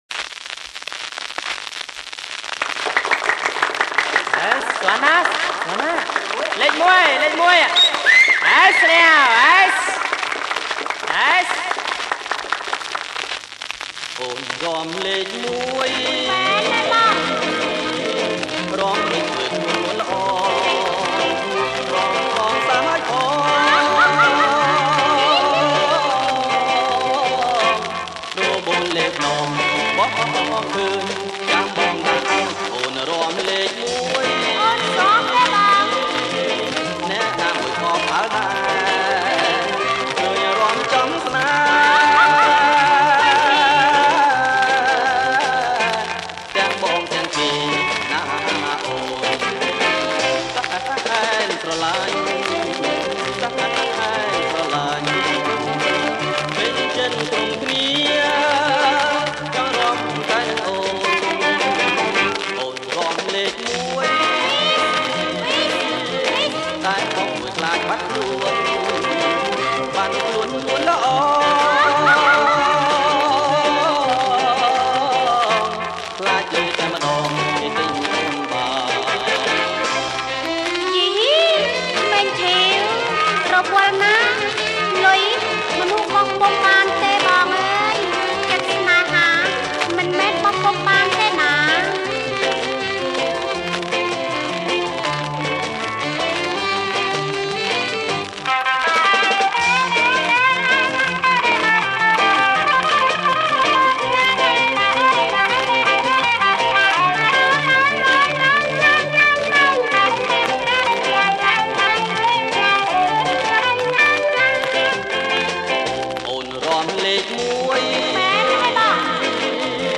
• ប្រគំជាចង្វាក់ Twist